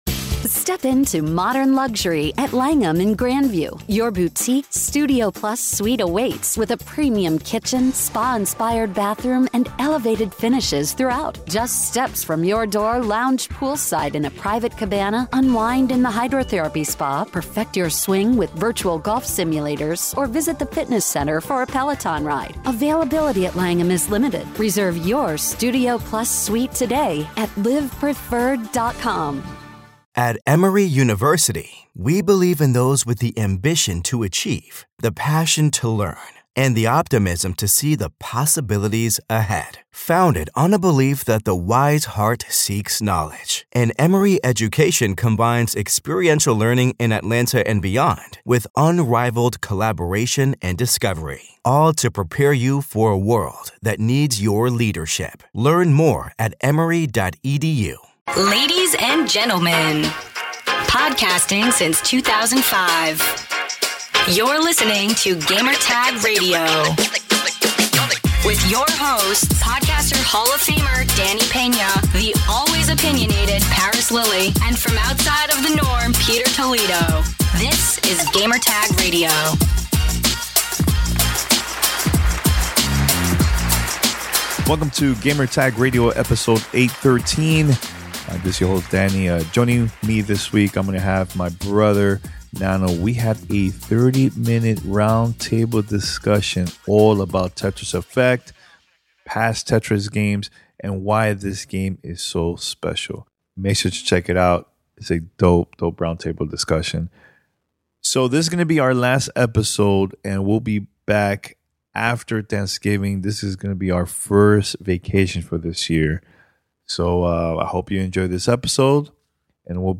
Roundtable discussion about why this game is so special.